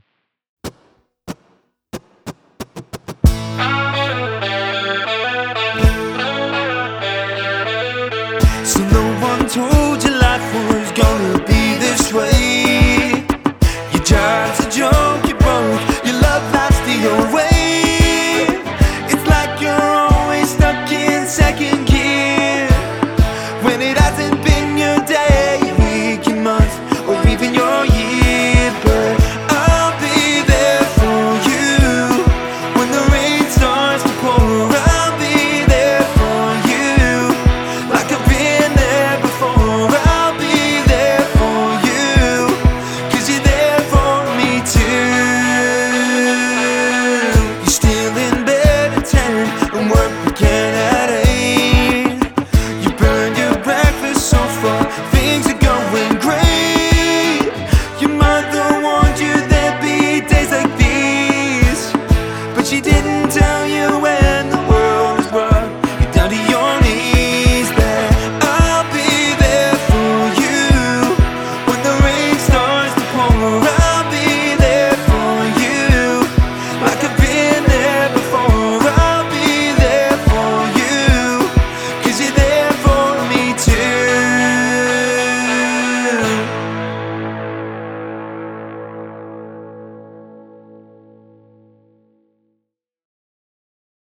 Dual Vocals | Guitar | Electric Guitar | Sax | MC
saxophone